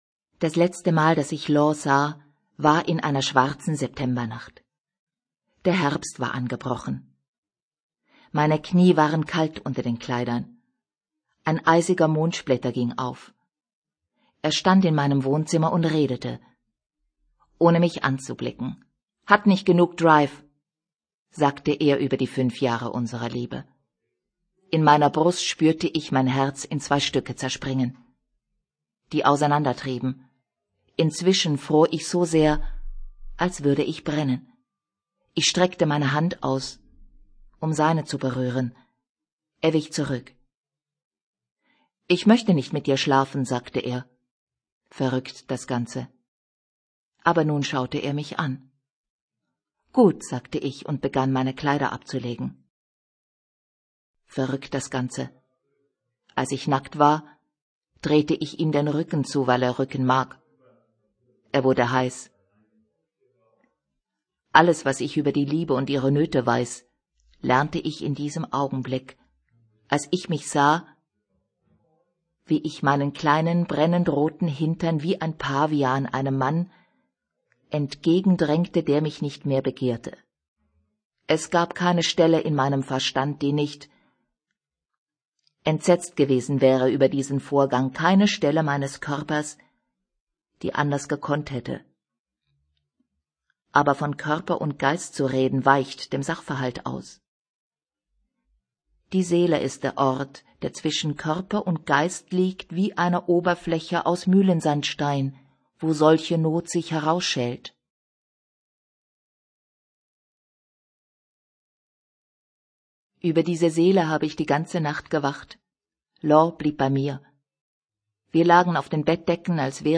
deutschsprachige Sprecherin (Schweiz) Verzauberung: großer Stimmumfang, schöne Modulation!
Sprechprobe: Werbung (Muttersprache):
german female voice over artist (switzerland)